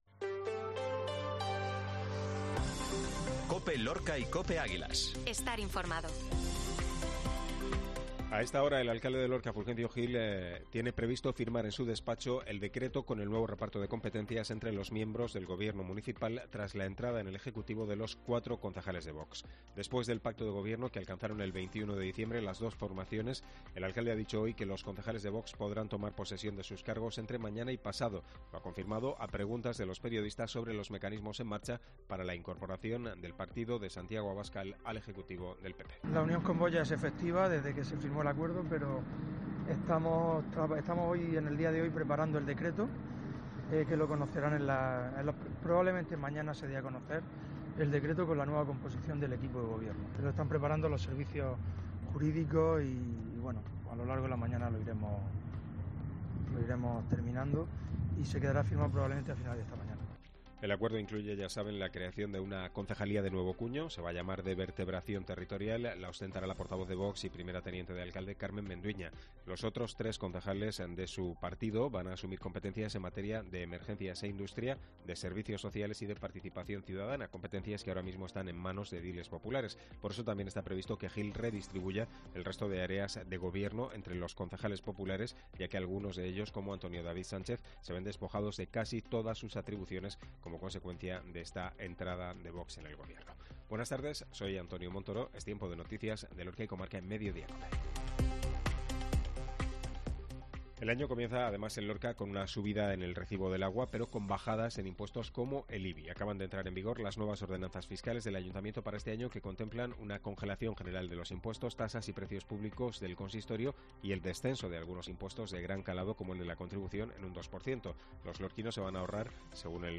INFORMATIVO MEDIODÍA COPE